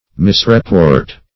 Search Result for " misreport" : The Collaborative International Dictionary of English v.0.48: Misreport \Mis`re*port"\, v. t. & i. To report erroneously; to give an incorrect account of.